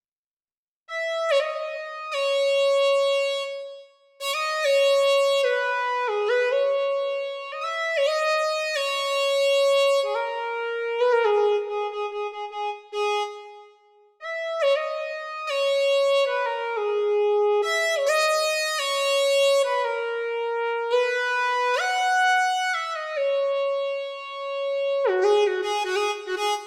05 lead C.wav